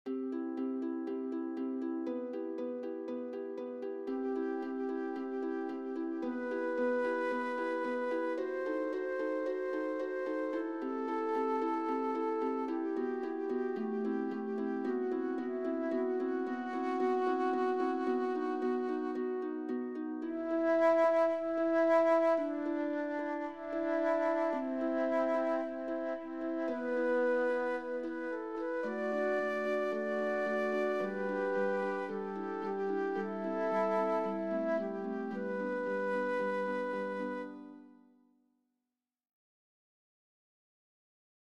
Sagrado ; Himno (sagrado)
SA TB O SB (2 voces Coro mixto O iguales )
arpa O Guitarra
Tonalidad : sol mayor